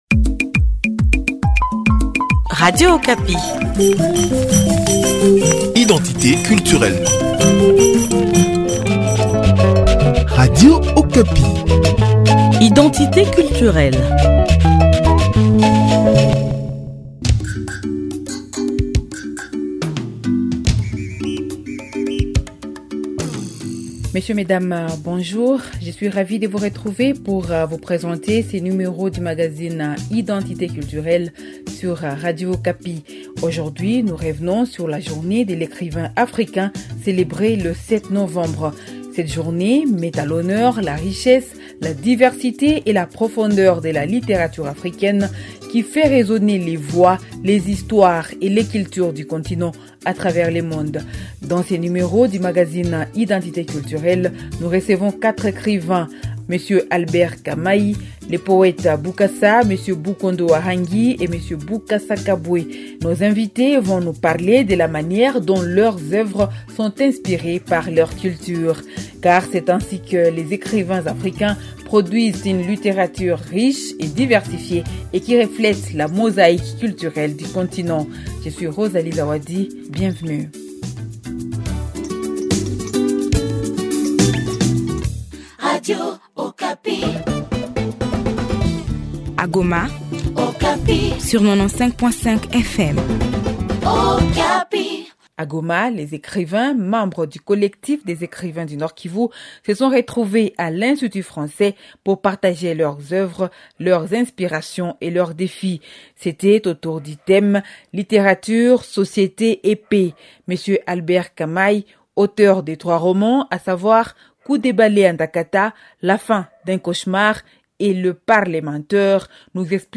Pour cette occasion, nous avons le plaisir de recevoir quatre écrivains congolais